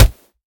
kick2.wav